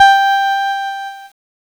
Cheese Note 13-G3.wav